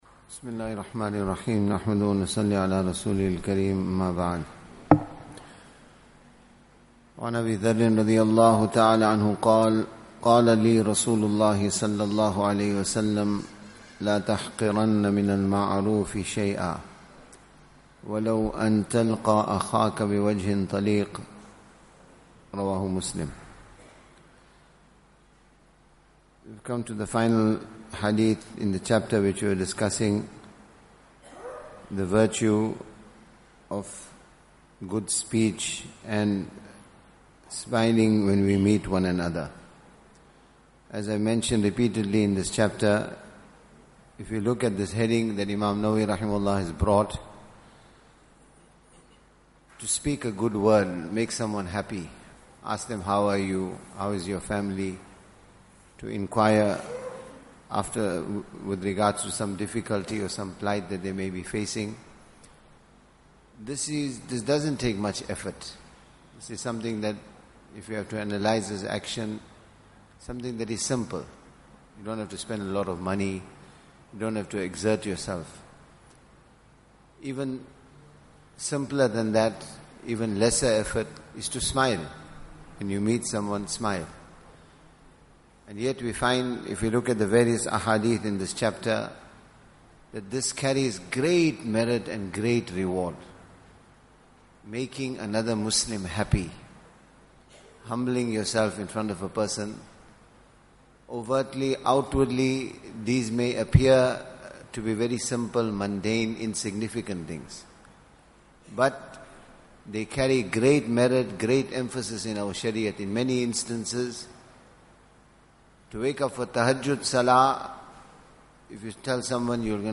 Morning Discourses